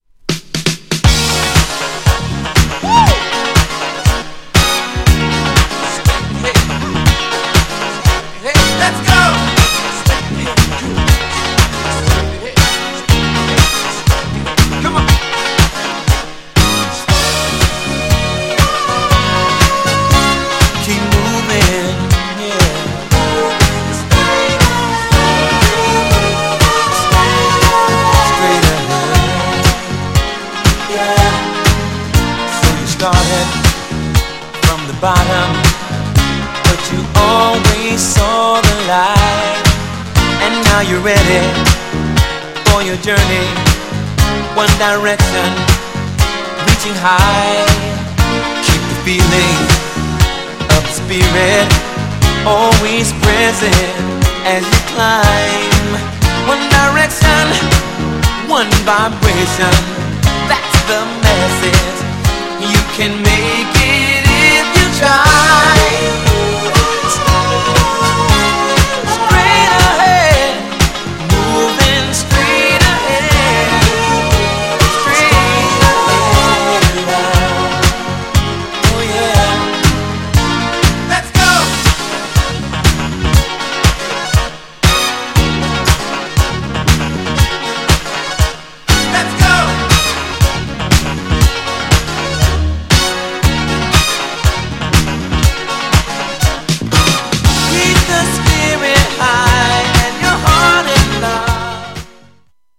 GENRE Dance Classic
BPM 116〜120BPM
HAPPY系サウンド
POP # キャッチー
ブラコン # メロディアス